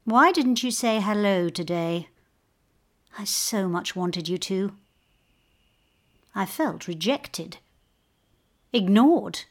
‘Touch’ and listen to the emotions and thoughts of this heartbroken woman.